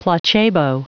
Prononciation du mot placebo en anglais (fichier audio)
Prononciation du mot : placebo